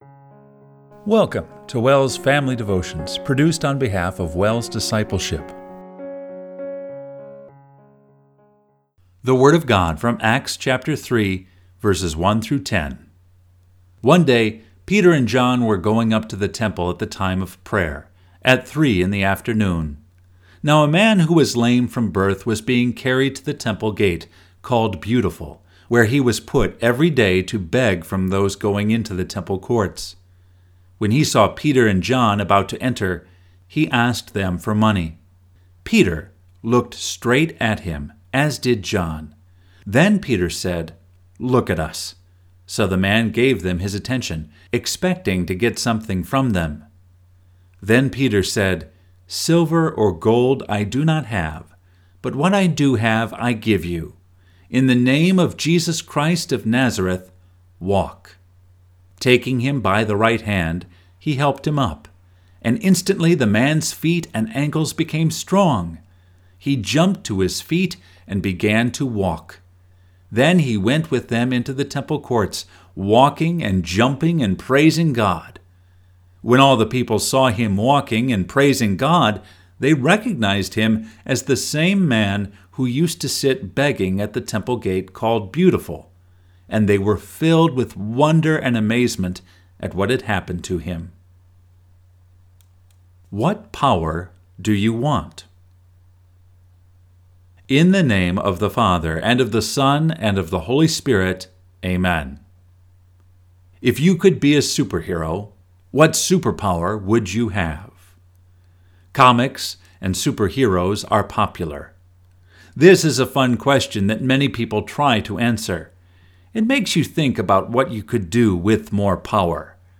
Family Devotion – September 11, 2024